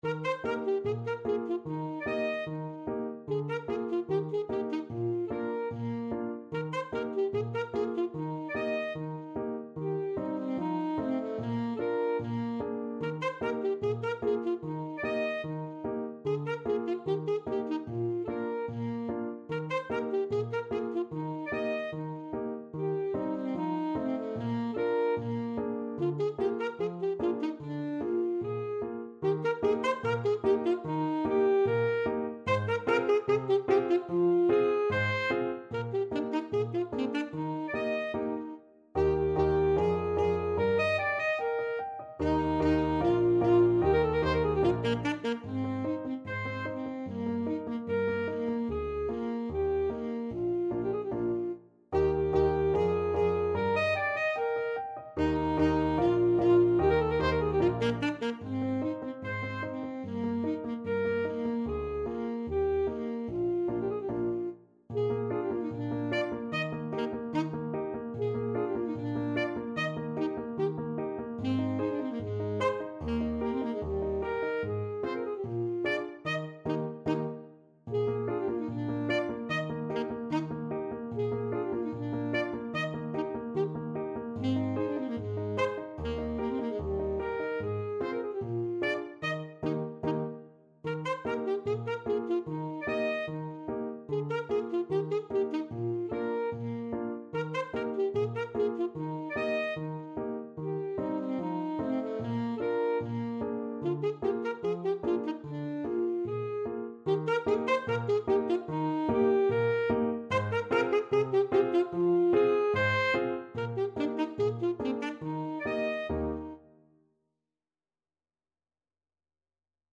Alto Saxophone
G4-Eb6
2/2 (View more 2/2 Music)
Allegretto = 74
Classical (View more Classical Saxophone Music)